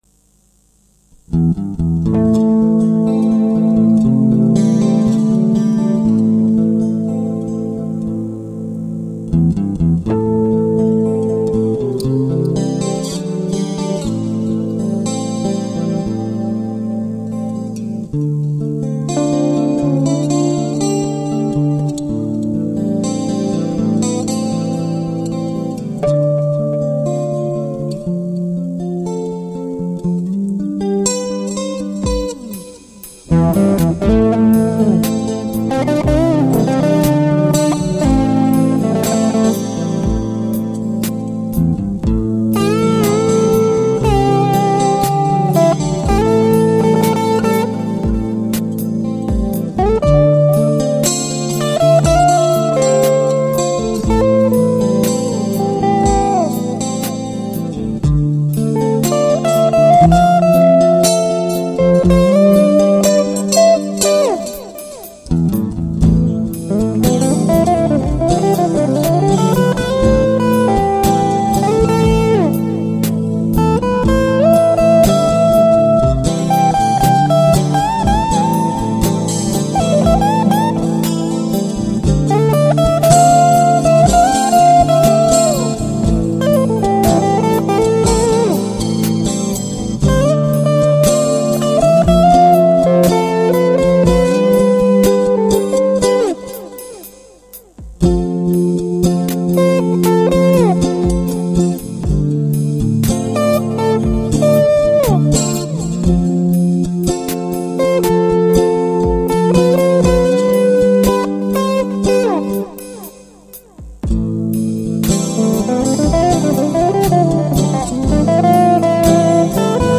TypEP (Studio Recording)
Instrumental